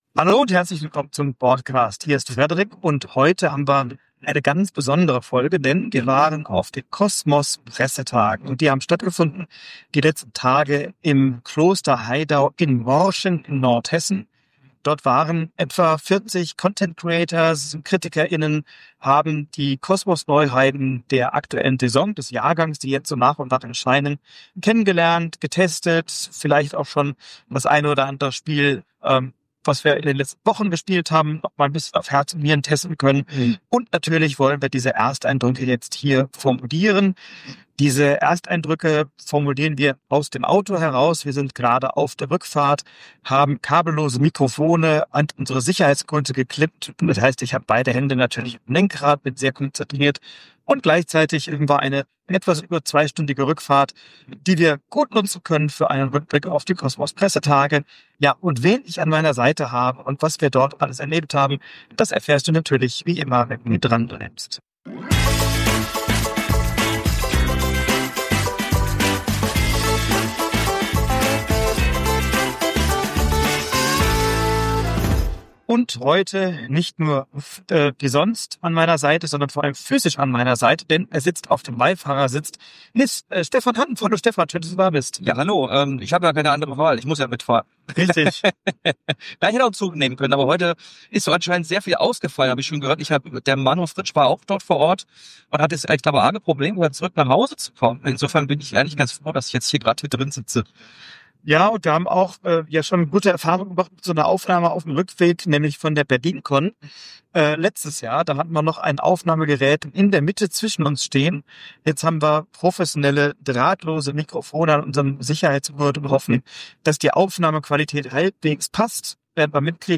Beschreibung vor 6 Monaten Auf den Kosmos-Pressetagen im Kloster Haydau in Morschen in Nordhessen haben wir zwei Tage nach Herzenslust alle Kosmos-Neuheiten ausprobieren und kennenlernen können. Bitte entschuldige die mittelmäßige Aufnahmequalität, wir haben die Folge im Auto aufgenommen und die Abmischungen waren mitunter schwieriger als gedacht.